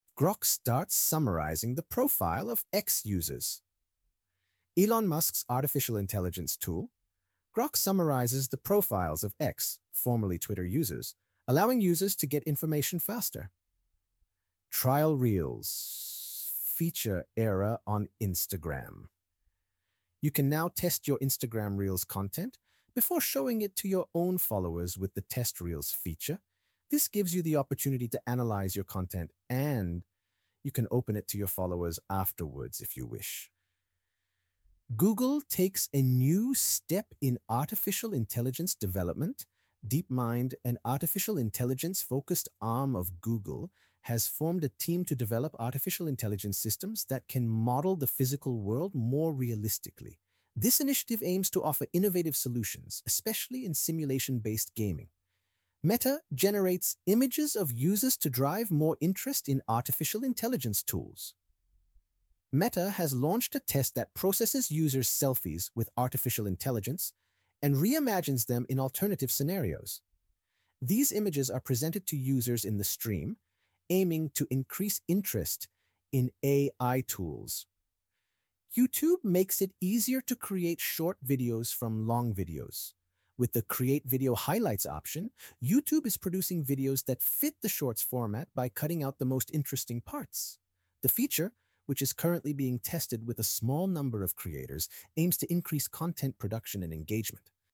Sosyal-Medya-Gelismeler-Ocak-2025-Sesli-Anlatim-EN-Versiyon.mp3